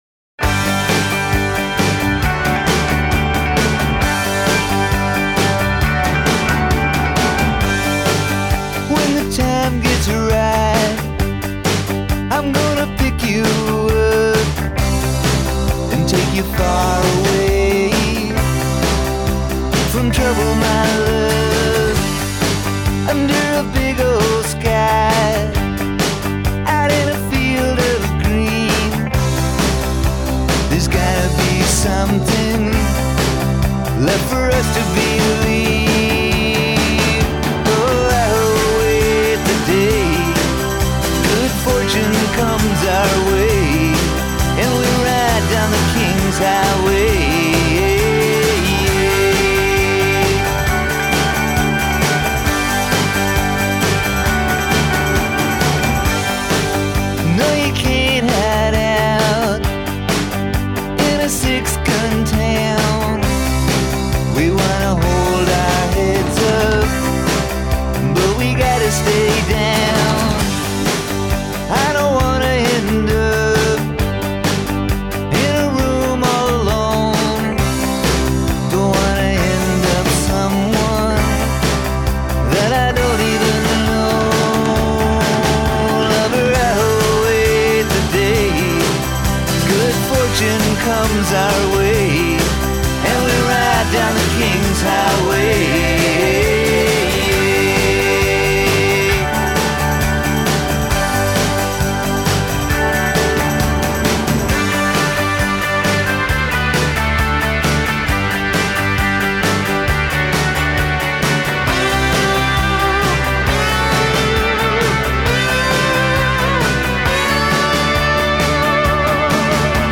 the twang in his voice